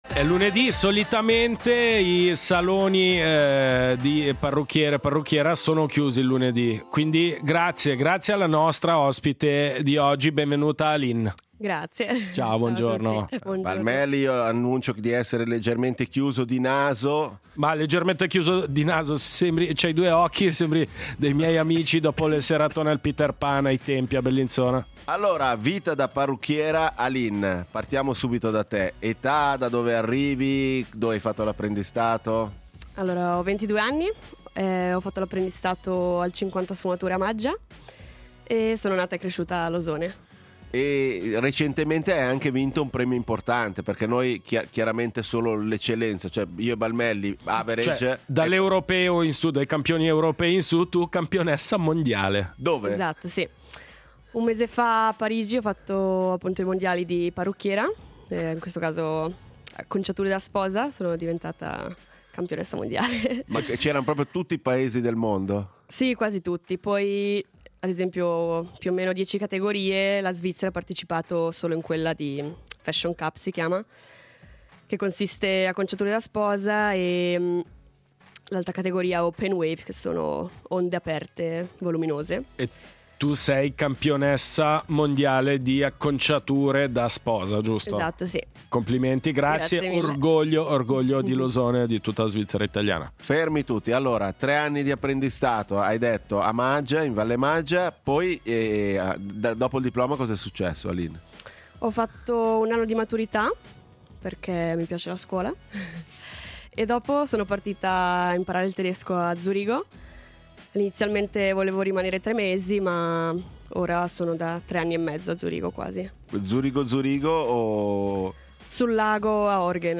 Ospite in studio